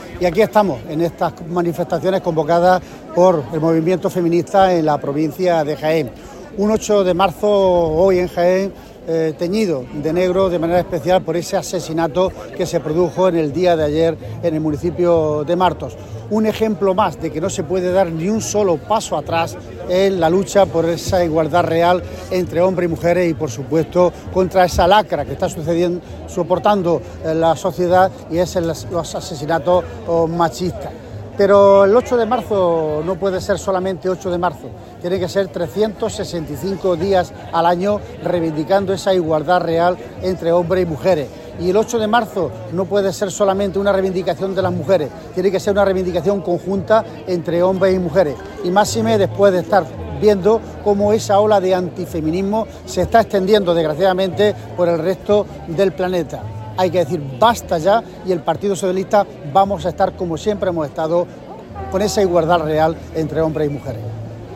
Cortes de sonido z Francisco Reyes